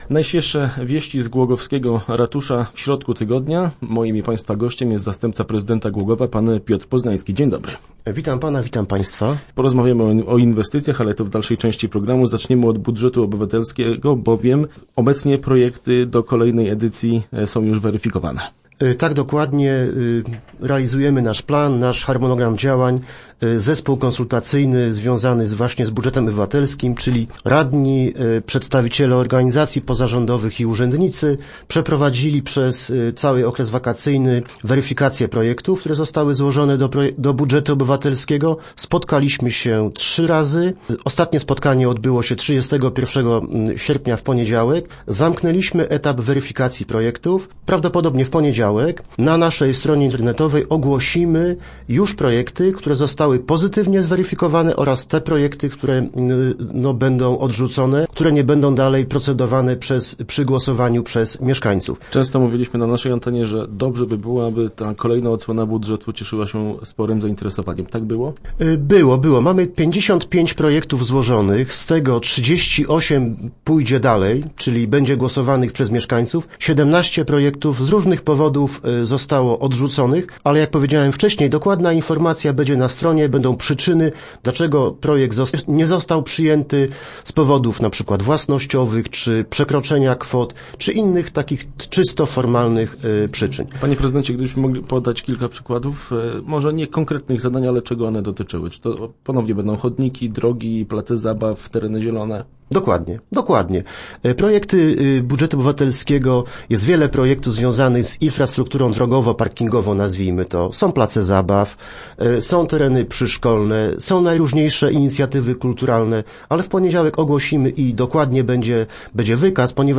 0513_re_poznanski.jpgNajprawdopodobniej już w poniedziałek na stronie UM ukażą się projekty, na które będzie można głosować w kolejnej edycji budżetu obywatelskiego. Zastępca prezydenta miasta Piotr Poznański był gościem środowych Rozmów Elki.